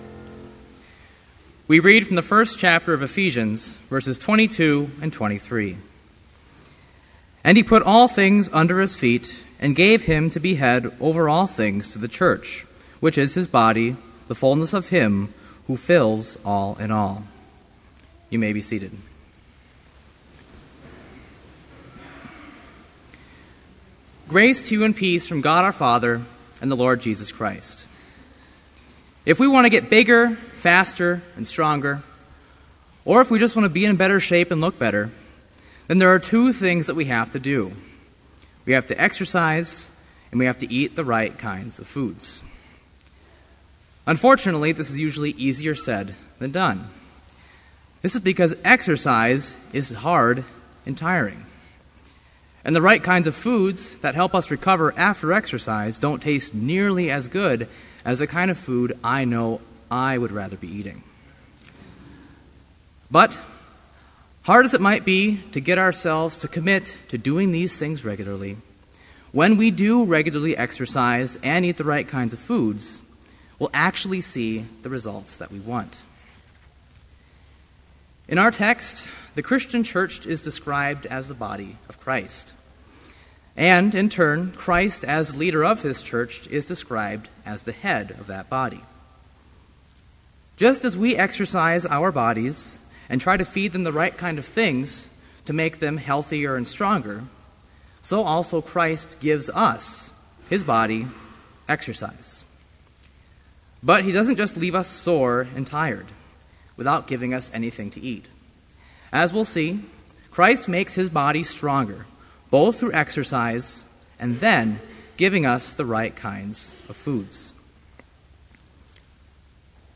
Complete Service
This Chapel Service was held in Trinity Chapel at Bethany Lutheran College on Tuesday, May 14, 2013, at 10 a.m. Page and hymn numbers are from the Evangelical Lutheran Hymnary.